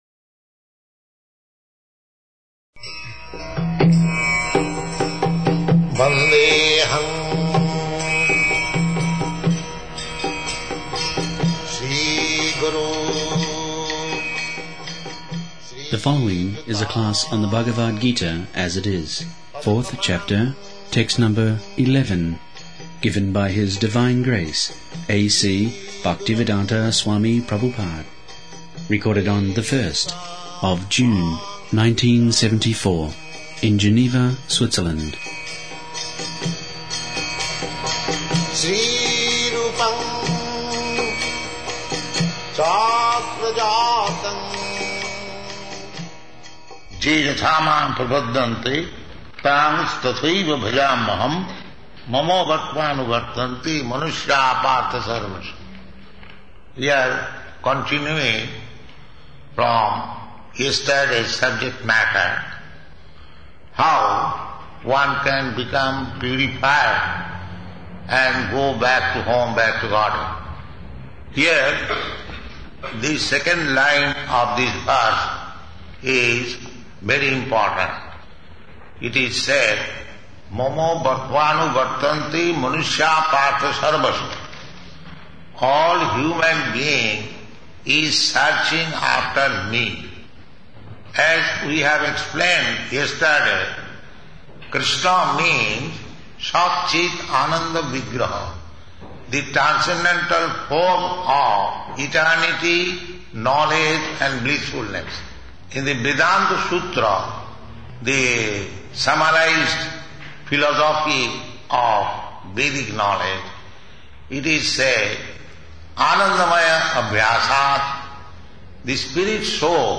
74/06/01 Geneva, Bhagavad-gita 4.11